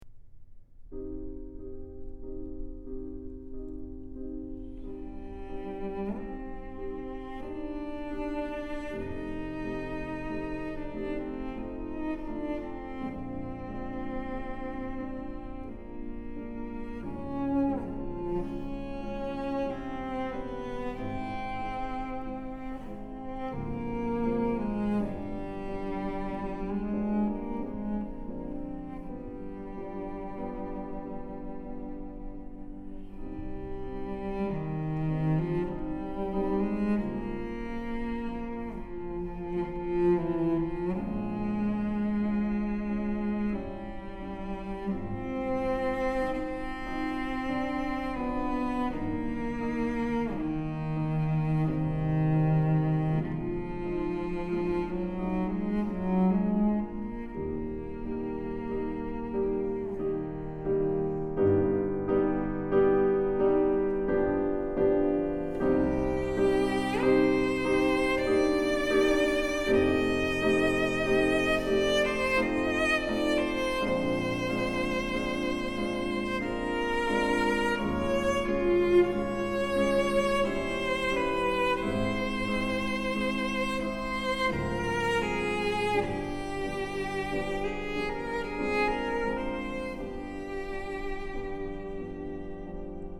☆才華洋溢、沈穩內斂的大師級演奏風範，不必刻意炫技卻更教人心動！
☆極簡錄音處理，再現最真實質樸且極具典雅氣質的樂器溫潤光澤。